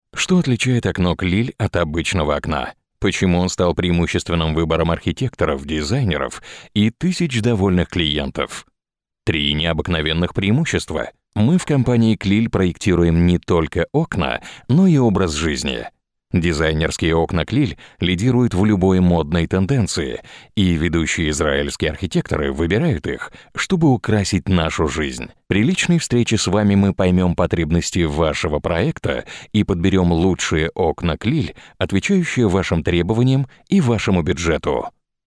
Russian – male – AK Studio